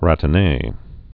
(rătə-nā)